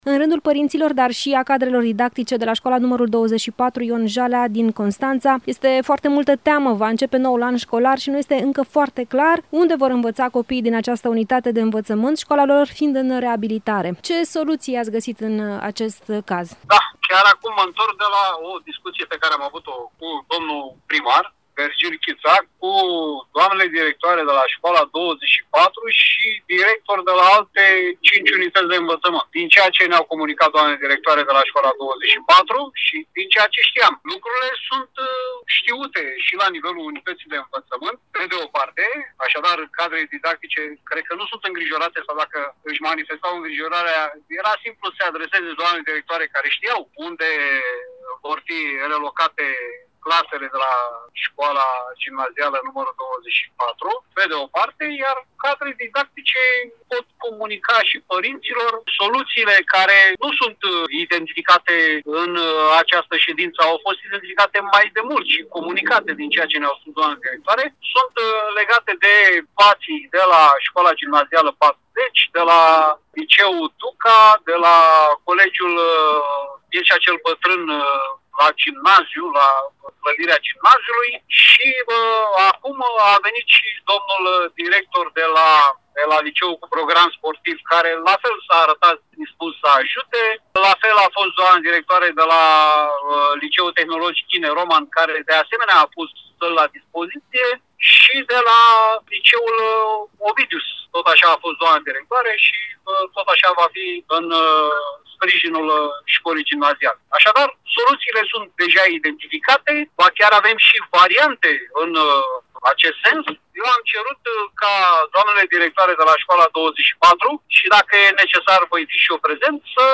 Contactat telefonic, inspectorul general al IȘJ Constanța, Sorin Mihai a declarat că, în urmă cu puțin timp, a discutat cu primarul Constanței, Vergil Chițac, dar și cu mai mulți directori de școli  și de licee și că au fost identificate soluții. Care sunt acestea, aflăm în interviul următor: